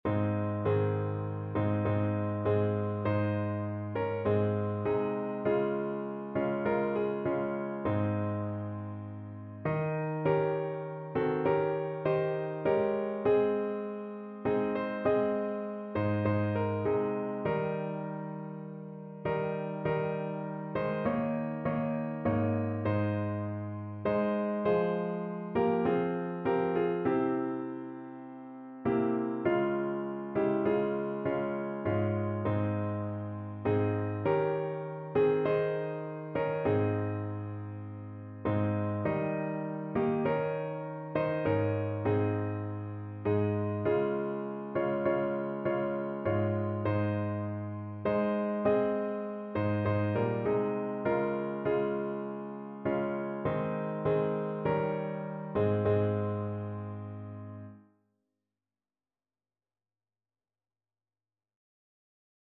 Evangeliumslieder Größe und Macht Gottes
Notensatz (4 Stimmen gemischt)